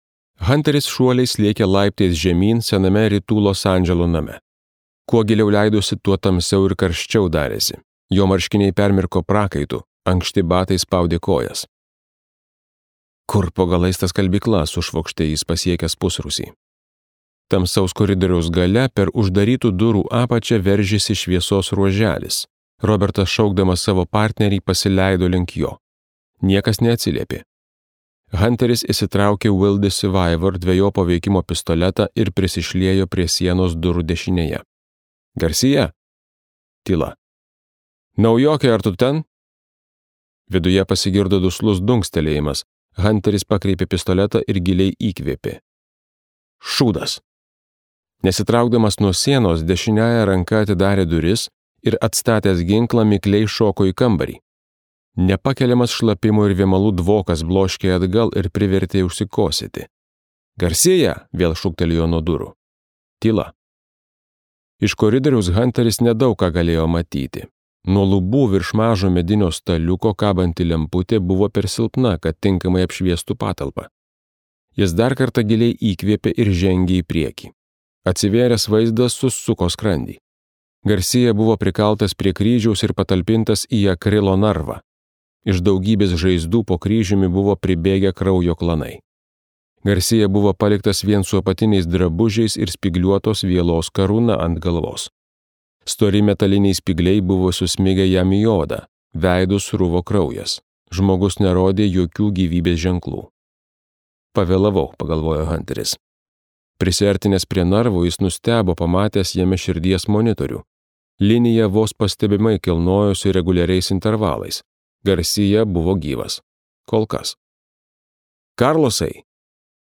Krucifiksas | Audioknygos | baltos lankos